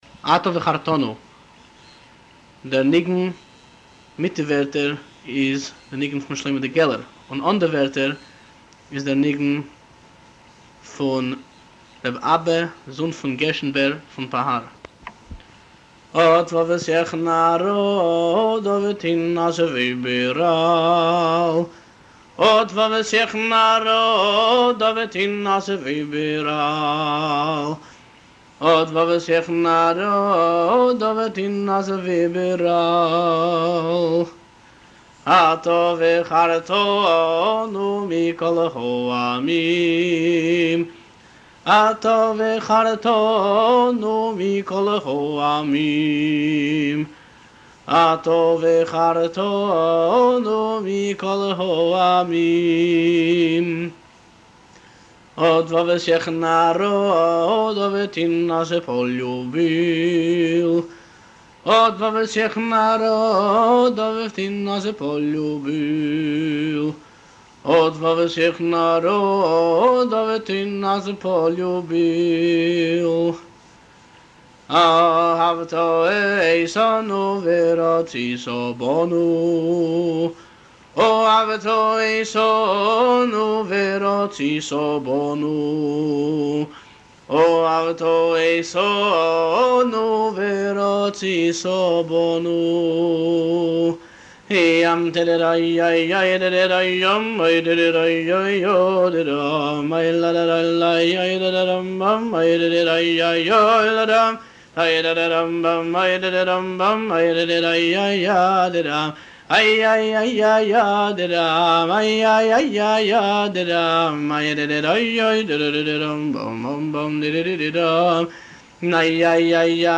הניגון
הבעל-מנגן